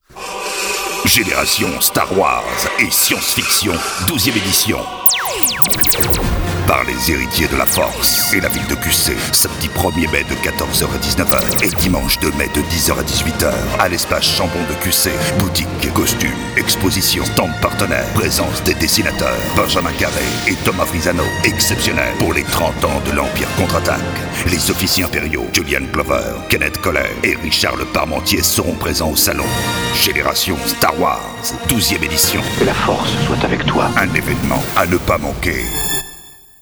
A l’approche de la convention vous pouvez notamment y entendre un jingle de Pub faisant la promotion de notre salon.
Spot-generation-Star-Wars-12e-edition.WAV